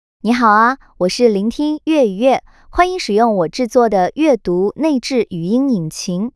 [TTS引擎] 豆包AI 语音引擎 V1